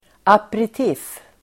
Ladda ner uttalet
aperitif substantiv, aperitif Uttal: [aperit'if:] Böjningar: aperitifen, aperitifer Synonymer: aptitretare Definition: drink före måltid, aptitretare (an alcoholic drink taken before a meal to stimulate the appetite)